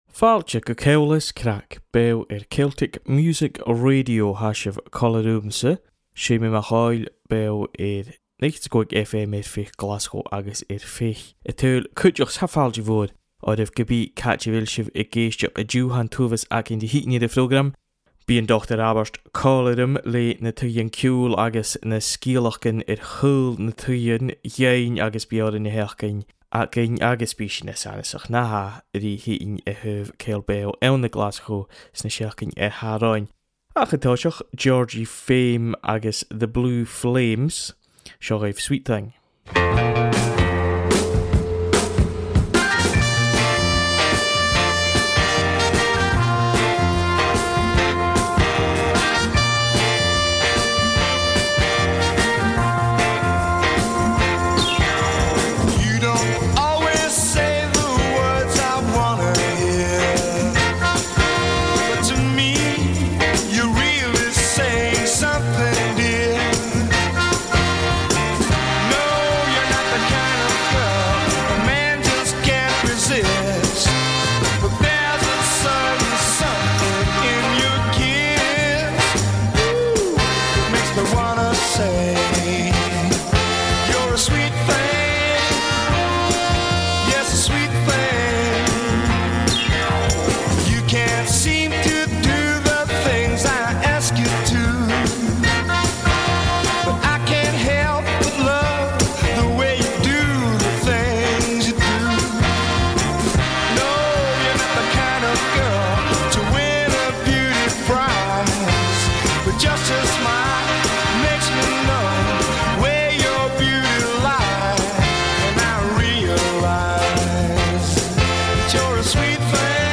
Ceòl is Craic @ Celtic Music Radio is our weekly broadcast featuring the best of new Gaelic music alongside an eclectic mix of jazz, electronica, Americana, world music
Radio